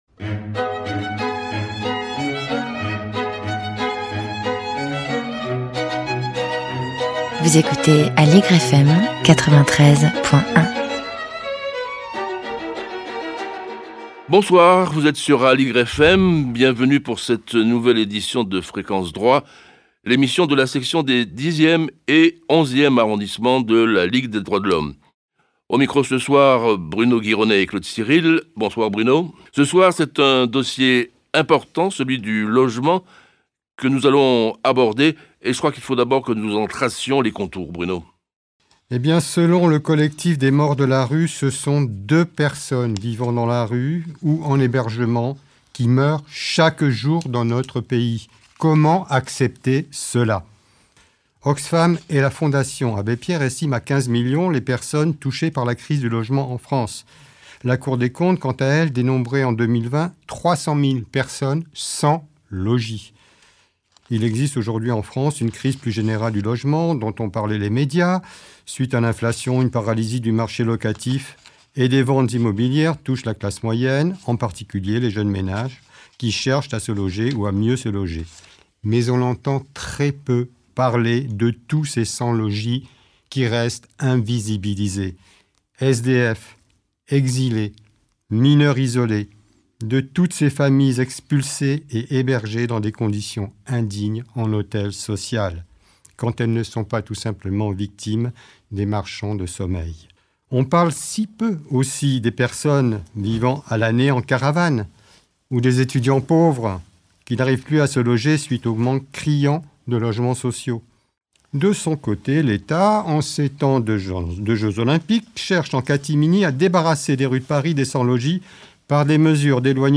Pour débattre de cette situation Fréquence Droits recevra sur son plateau trois militants associatifs engagés dans la défense du droit au logement :